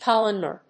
音節col・um・nar 発音記号・読み方
/kəlˈʌmnɚ(米国英語)/